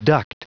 Prononciation du mot duct en anglais (fichier audio)
Prononciation du mot : duct